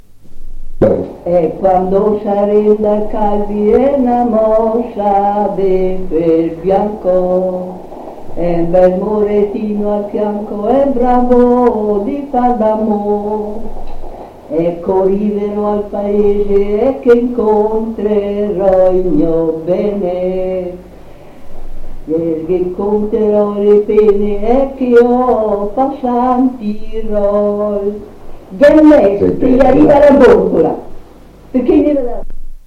Post 1975. 1 bobina di nastro magnetico.